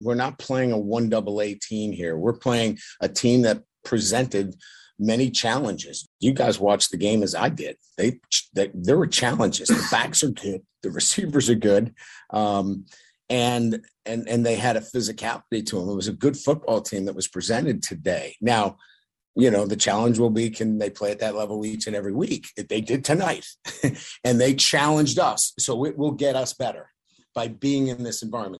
Notre Dame head Coach Brian Kelly evaluated the team’s performance postgame and chalked up most of their struggles to weak defensive play.